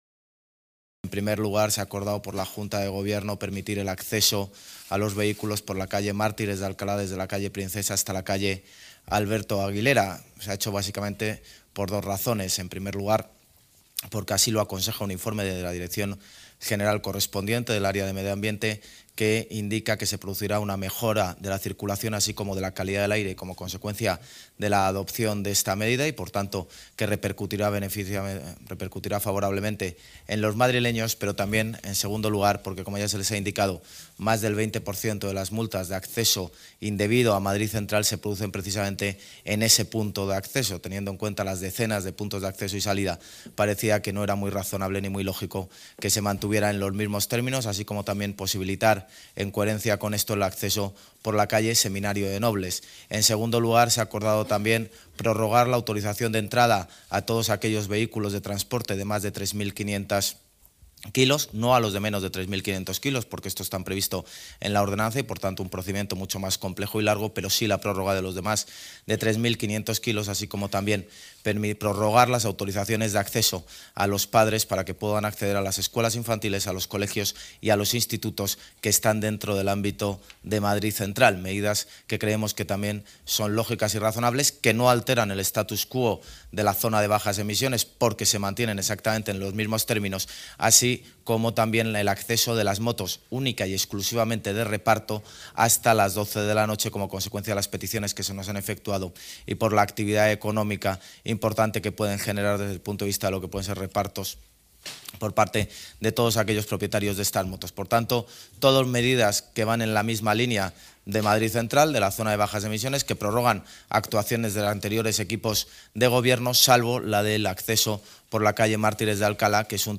Nueva ventana:El alcalde de Madrid, José Luis Martínez-Almeida, explica los motivos de la modificación del perímetro de Madrid Central